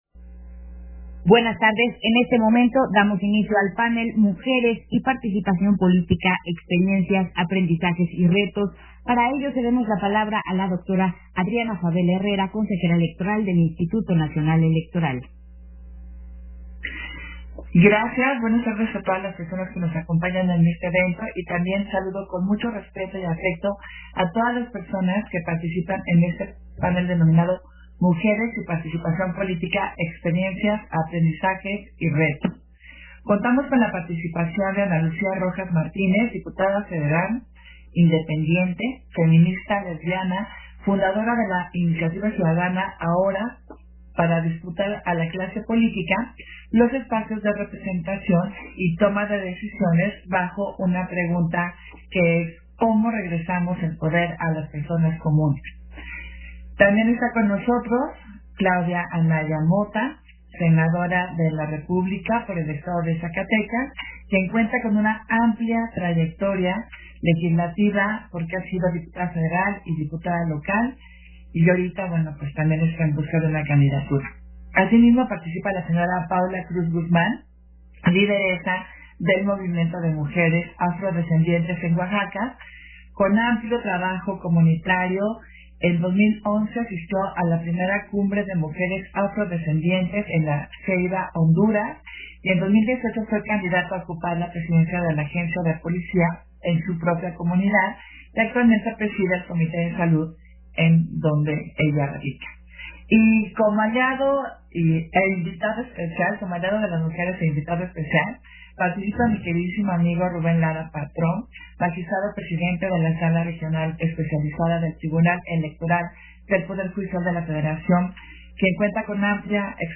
«DR0000_4379.mp3» de TASCAM DR-05.
Versión estenográfica del panel Mujeres y Participación Política, del Foro Derechos políticos y electorales de la mujeres